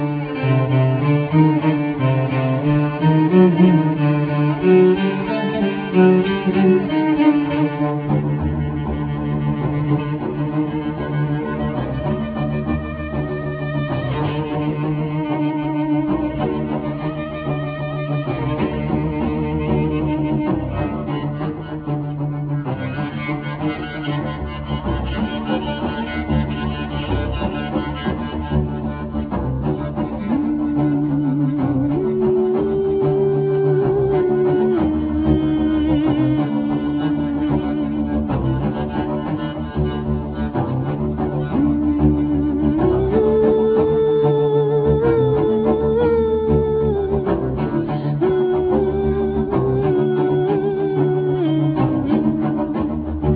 Cello,Vocals